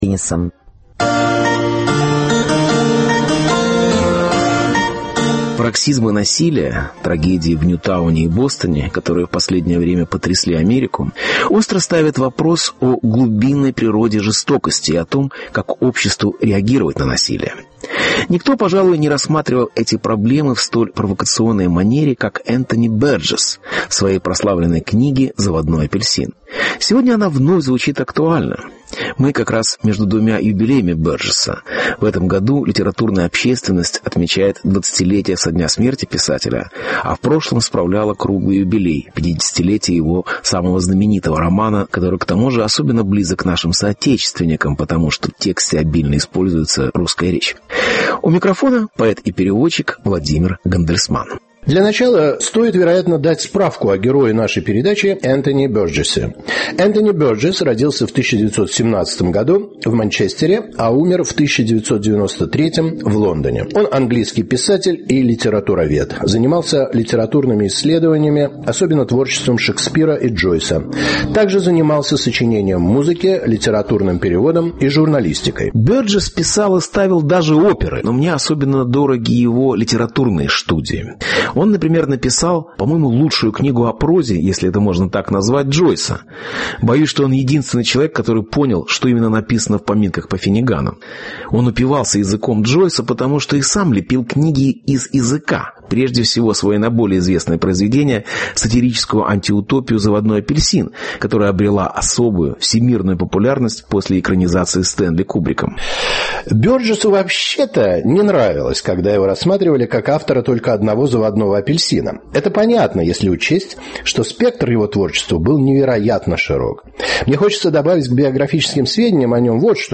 Энтони Бёрджис и его притча о насилии (Беседа